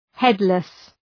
Προφορά
{‘hedlıs}